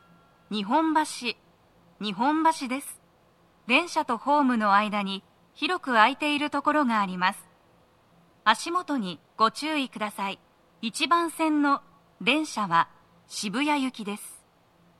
スピーカー種類 TOA天井型
足元注意喚起放送が付帯されています。
1番線 渋谷方面 到着放送 【女声